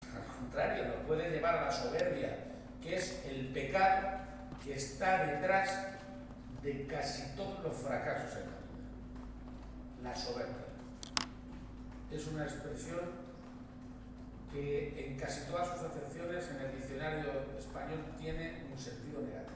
Así lo ha subrayado durante su toma de posesión como presidente de la Junta de Comunidades de Castilla-La Mancha por la fórmula de juramento; acto que se ha celebrado este sábado en el Palacio de Fuensalida, sede de la Presidencia autonómica y al que han asistido cerca de 550 personalidades del mundo político, social, cultural, sindical y empresarial, además de familiares y amigos.